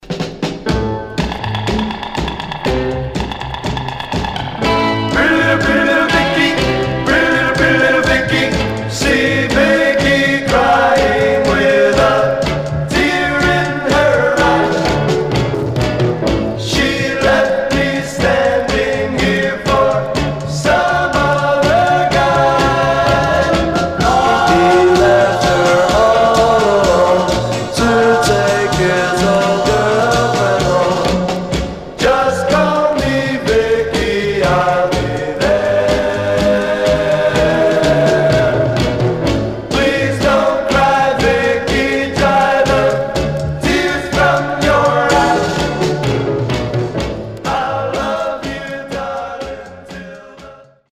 Surface noise/wear
Mono
Teen